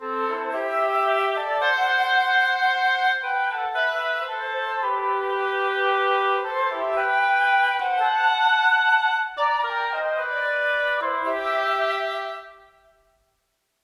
A tiny little bonus for those of you who made it all the way to the end—one of the little bits of practicing I’ve been doing nearly every morning yielded a more interesting melody than I have historically written, and I did a tiny bit of work this evening to see how it would sound with a little bit of counterpoint: listen here .